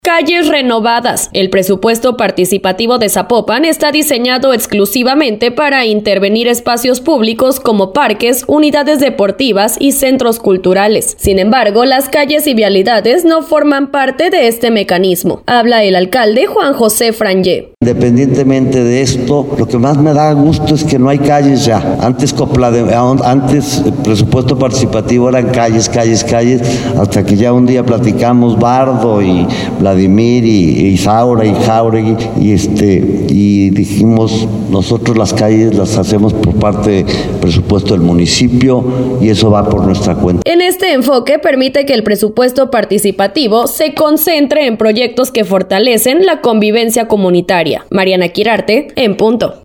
Habla El Alcalde Juan Jose Frangie